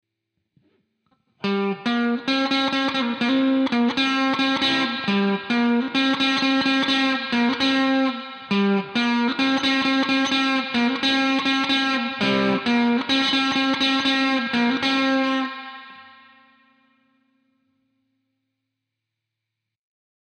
Einmal Gitarre clean und einmal Gitarre clean, mit dem eingebauten AIR Effekt.
scarlett-guitar-1.mp3